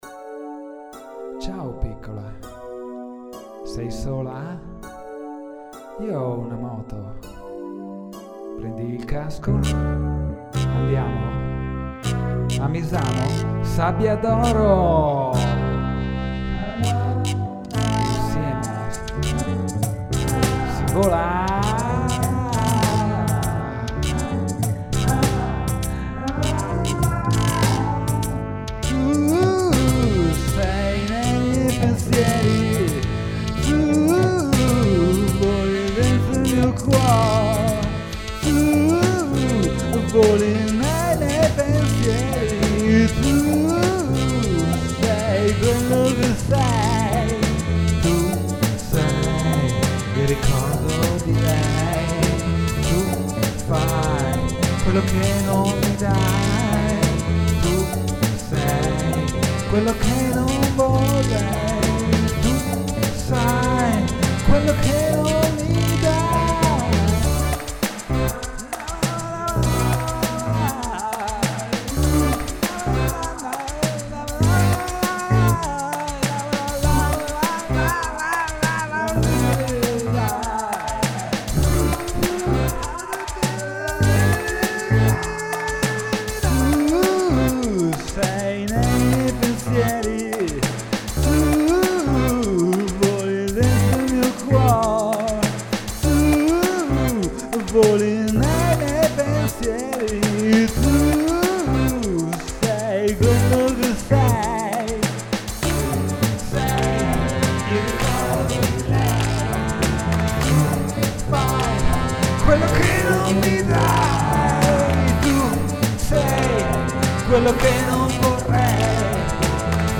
basso
vocette isteriche